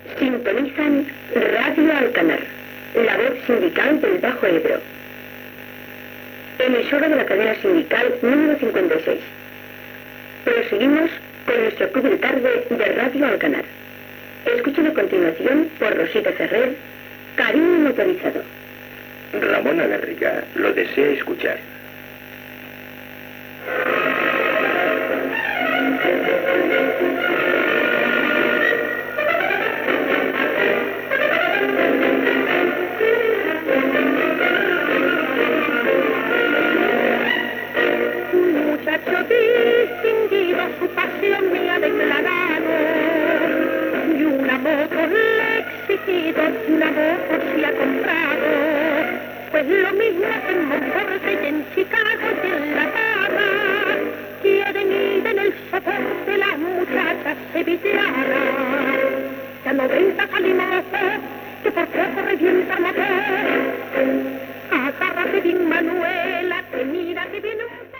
Identificació i tema musical sol·licitat.
Musical